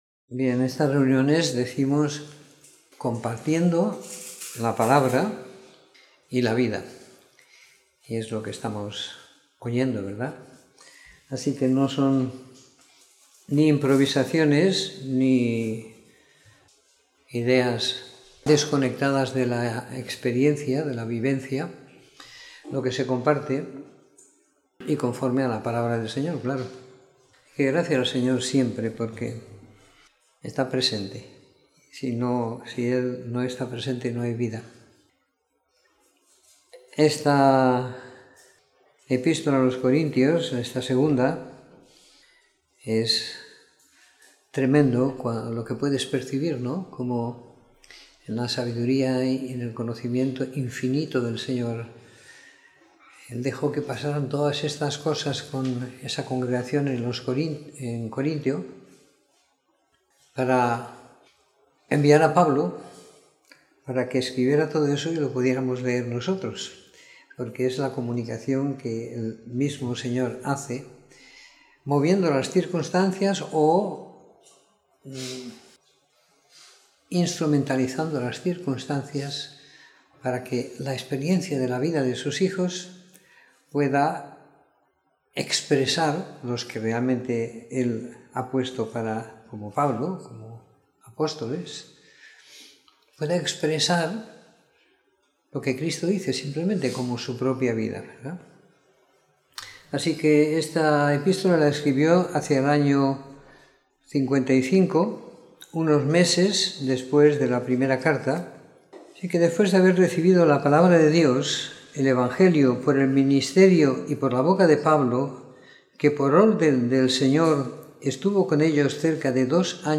Comentario en la epístola de 2ª Corintios siguiendo la lectura programada para cada semana del año que tenemos en la congregación en Sant Pere de Ribes.
Escuchar la Reunión / Descargar Reunión en audio Comentario en la epístola de 2ª Corintios siguiendo la lectura programada para cada semana del año que tenemos en la congregación en Sant Pere de Ribes.